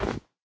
snow1.ogg